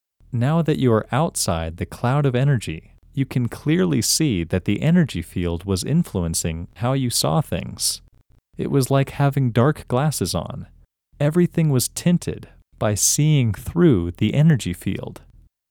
OUT – English Male 30